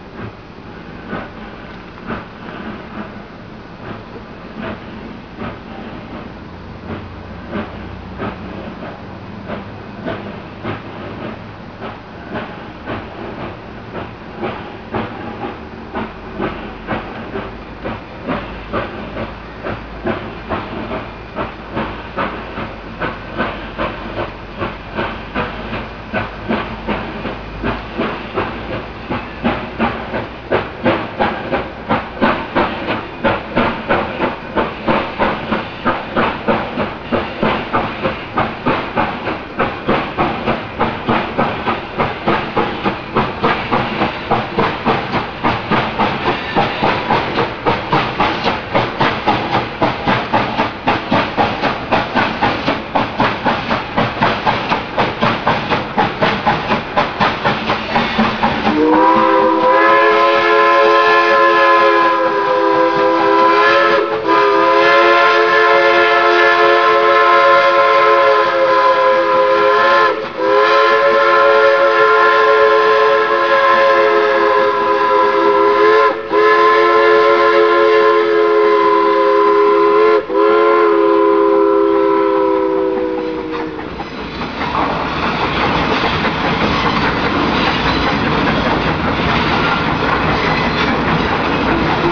during the October 2000 mixed freight, there was a runby at the raritain river bridge